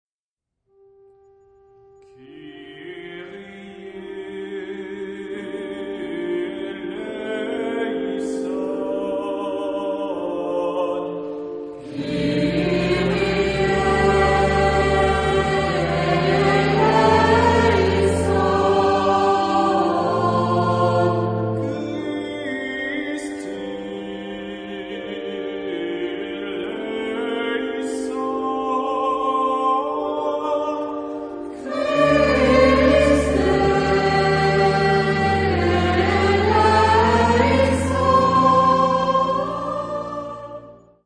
Genre-Style-Form: Mass
Mood of the piece: pleading
Type of Choir:  (1 unison voices )
Instruments: Organ (1)
Tonality: G minor